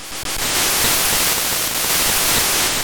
Wind.wav